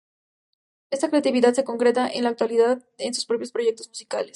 cre‧a‧ti‧vi‧dad
/kɾeatibiˈdad/